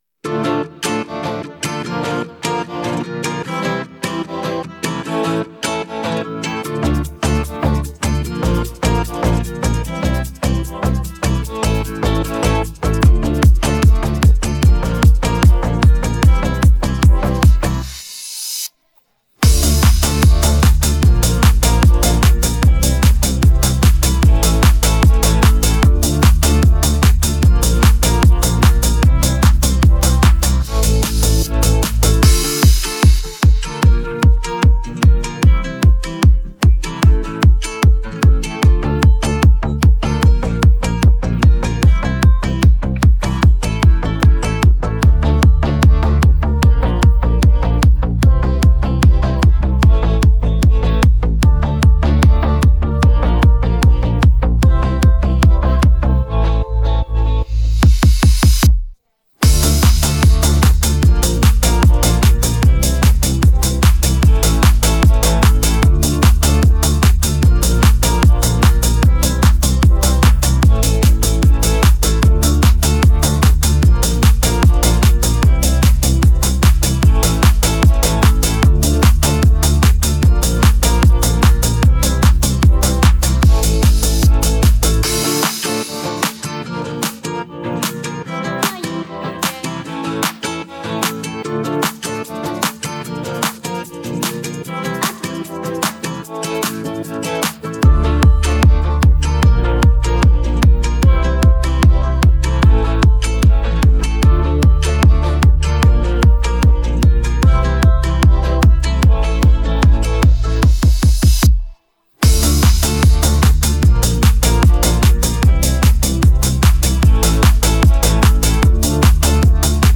Детские песни В закладки 😡 Замечание!